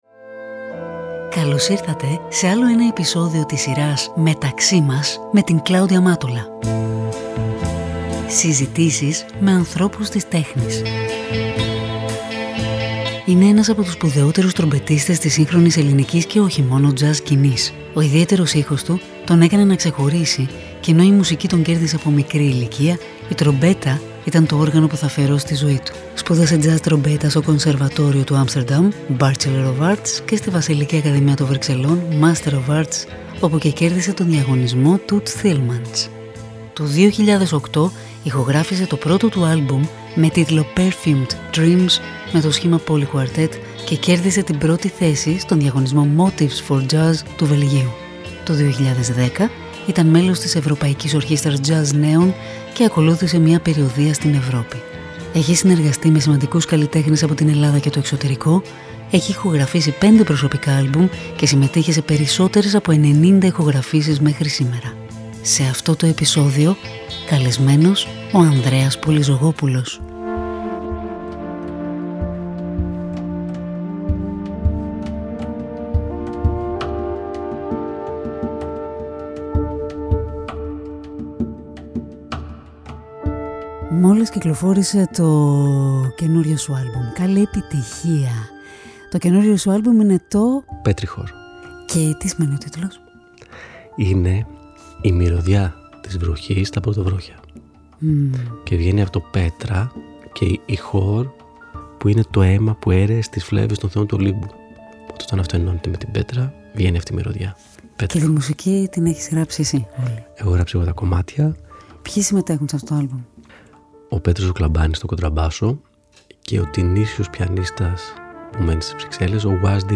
Μια οργανική συζήτηση με ανθρώπους της τέχνης.